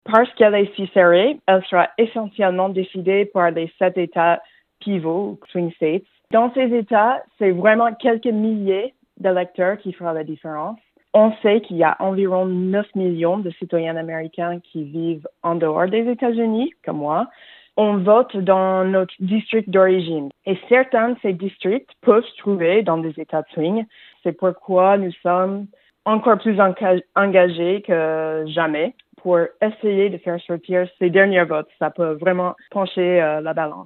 Explications et interview à Genève.